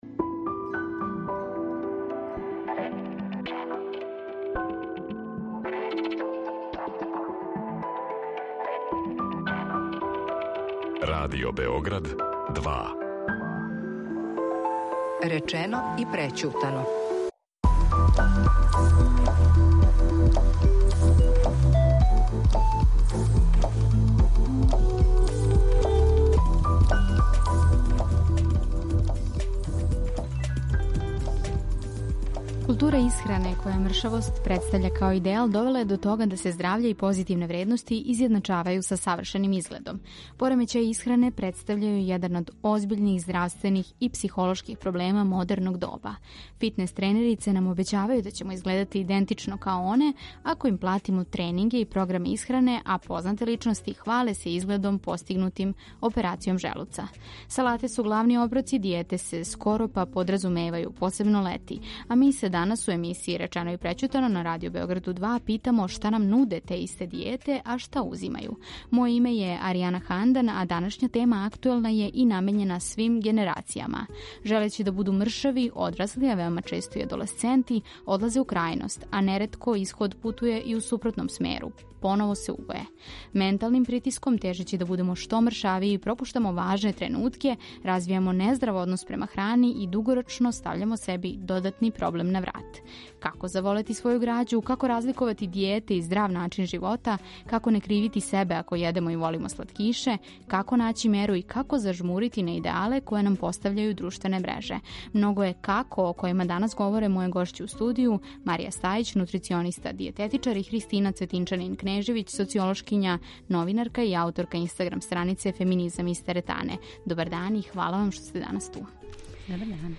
О томе говоре гошће у студију